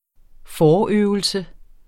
Udtale [ ˈfɒːˌøvəlsə ]